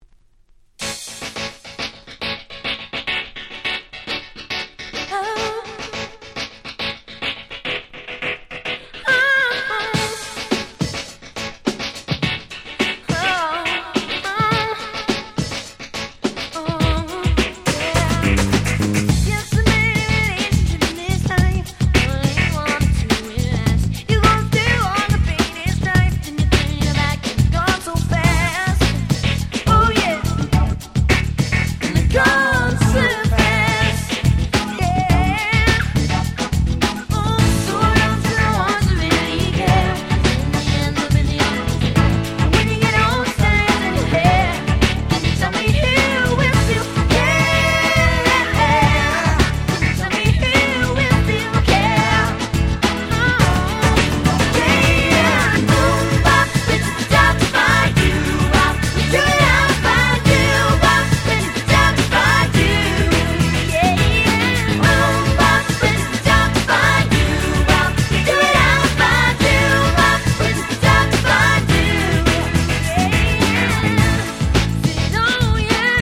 97' 世界的大ヒットPops !!